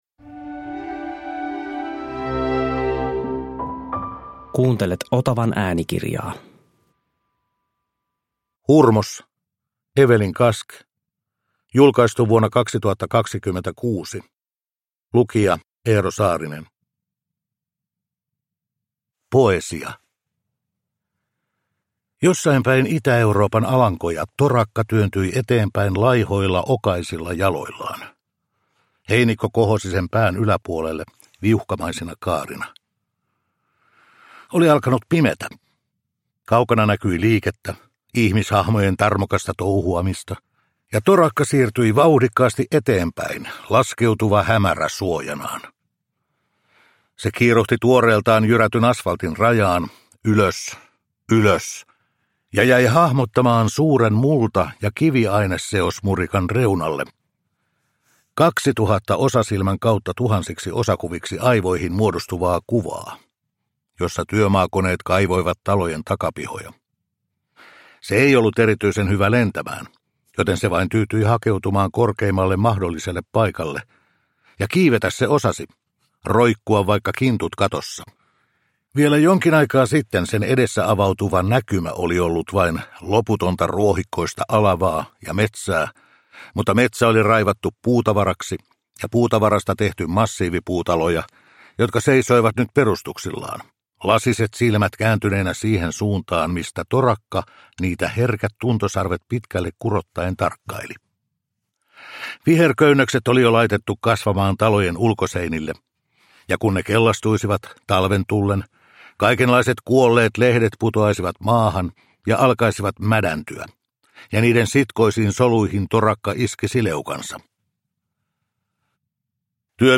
Hurmos – Ljudbok